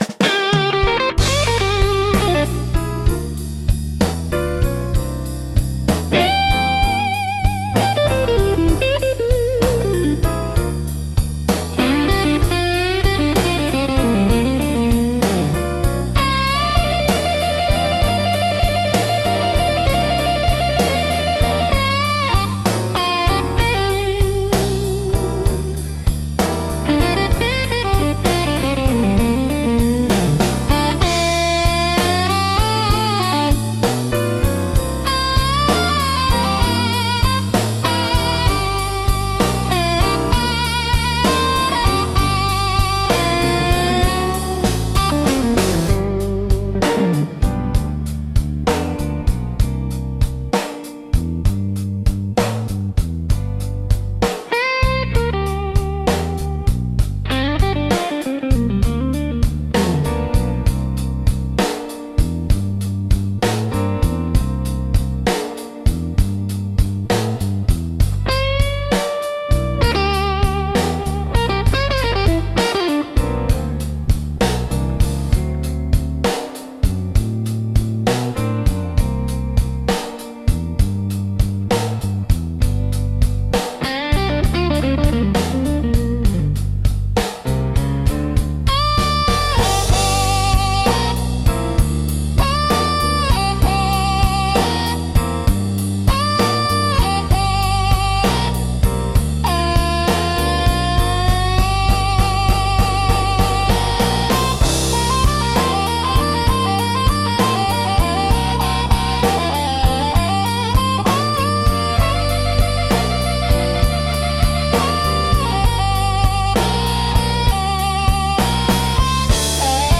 ギターやハーモニカによる哀愁のあるメロディとリズムが、深い感情表現を生み出します。